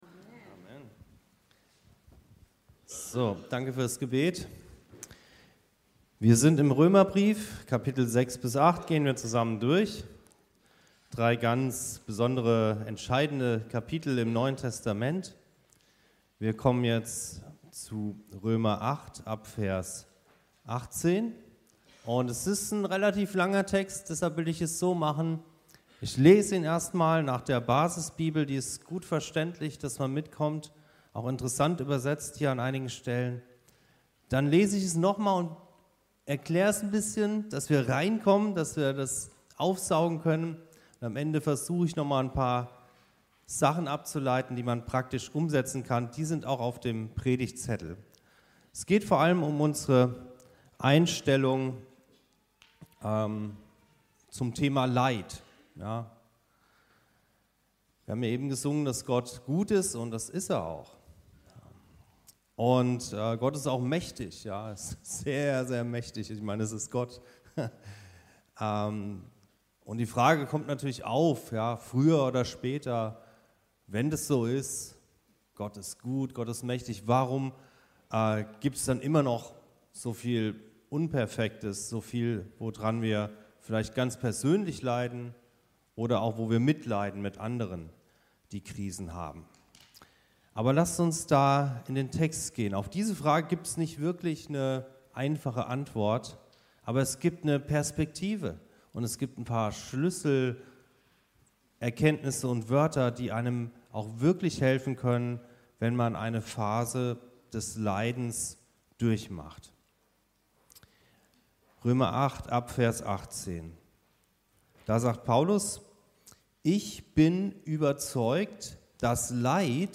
Das dreifache Seufzen ~ Anskar-Kirche Hamburg- Predigten Podcast